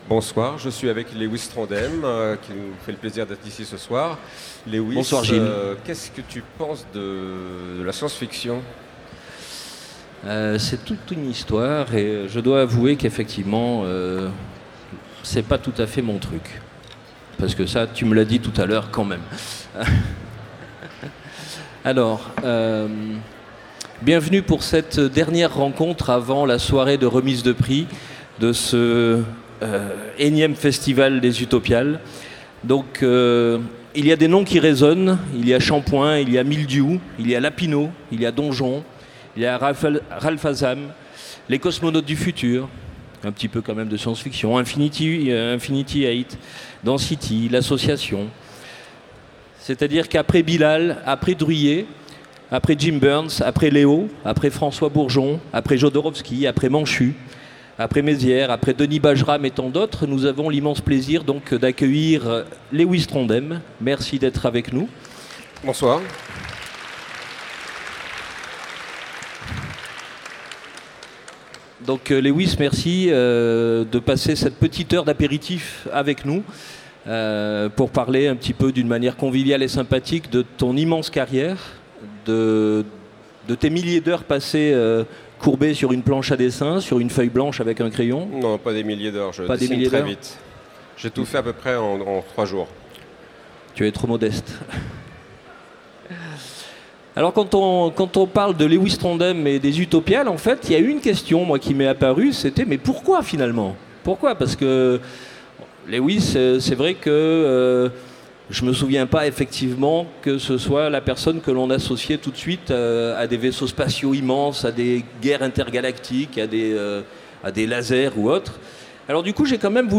- le 15/11/2017 Partager Commenter Utopiales 2017 : Rencontre avec Lewis Trondheim Télécharger le MP3 à lire aussi Lewis Trondheim Genres / Mots-clés Rencontre avec un auteur Conférence Partager cet article